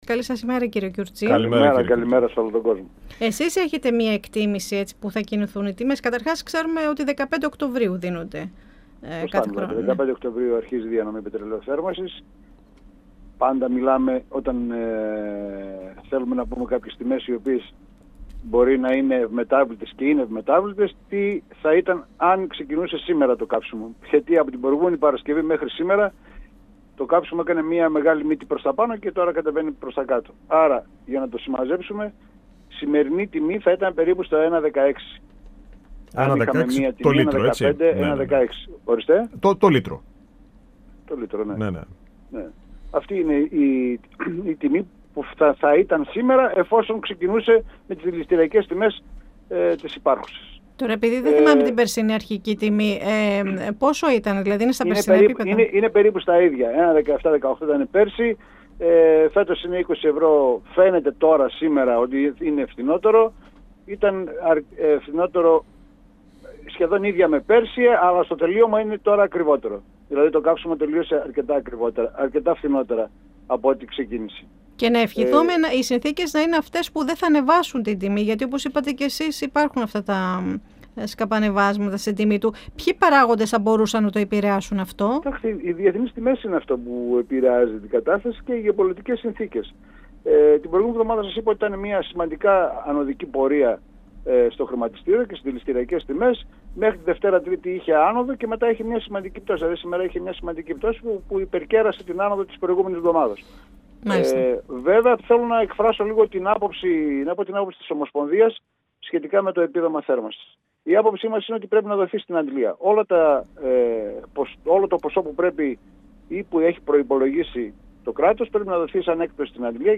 να δίνεται στην αντλία η επιδότηση για το πετρέλαιο θέρμανσης. 102FM Αιθουσα Συνταξης Συνεντεύξεις ΕΡΤ3